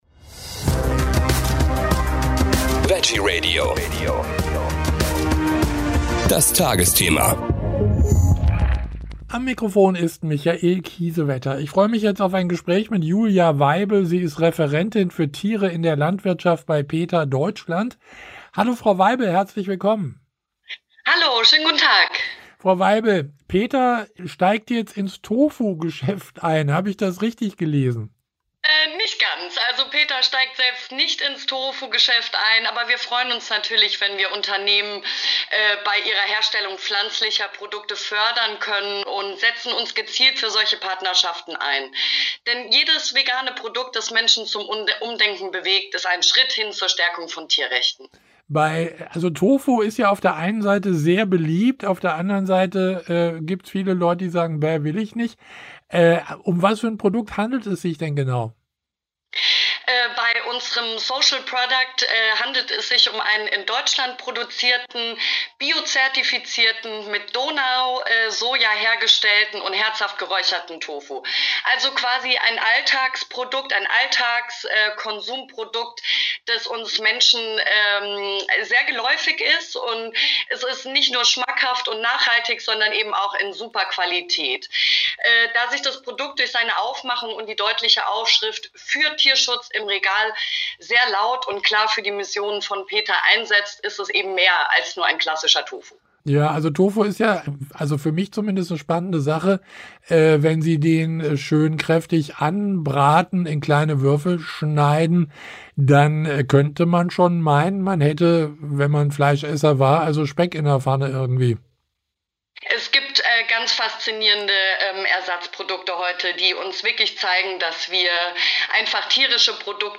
Wir haben mit ihr gesprochen.